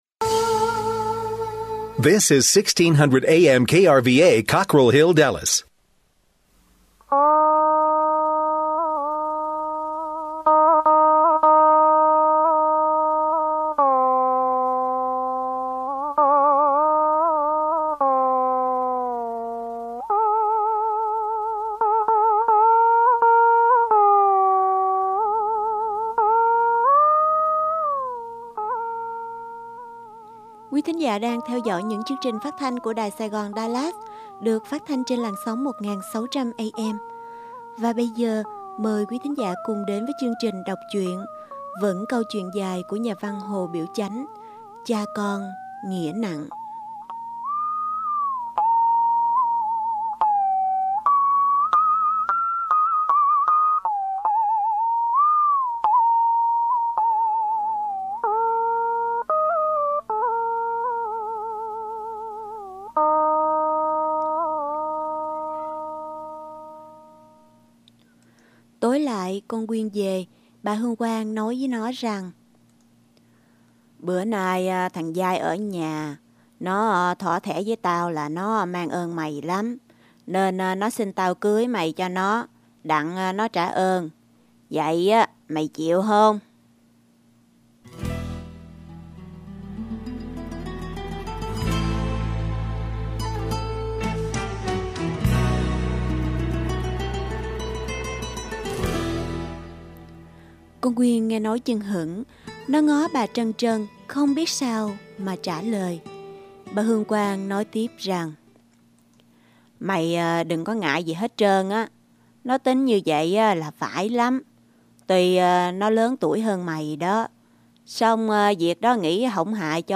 Truyện Đọc: Cha Con Nghĩa Nặng 9